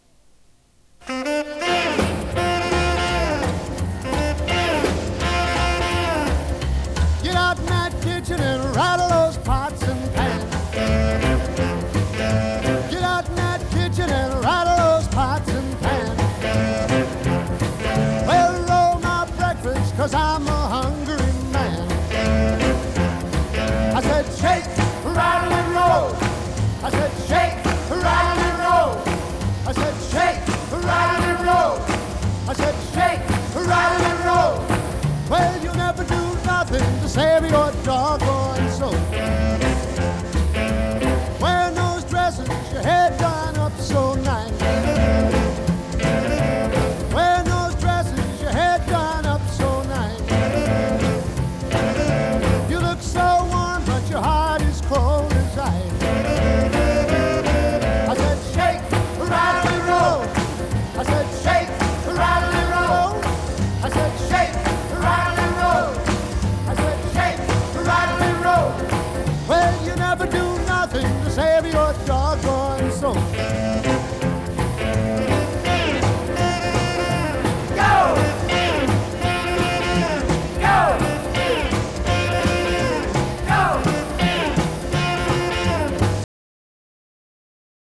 Deze muziek werd Rock and Roll genoemd.